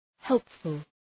Προφορά
{‘helpfəl}